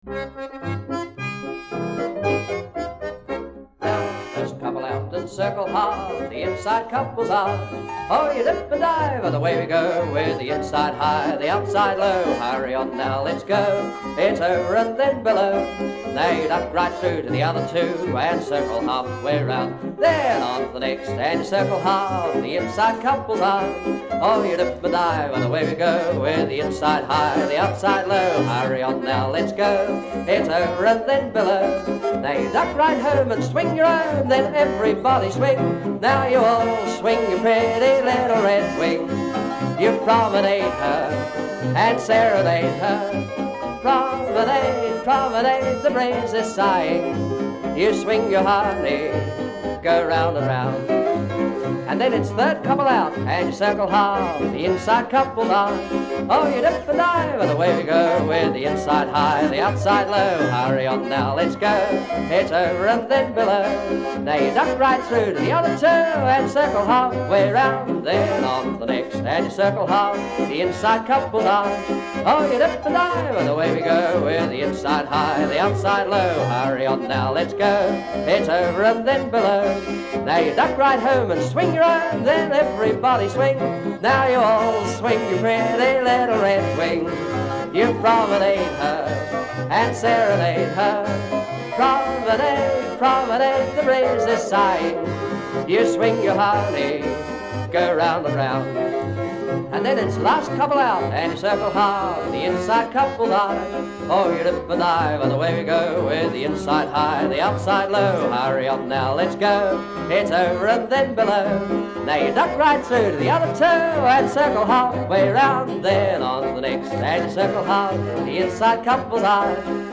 STUDIO PRODUCED (MP3)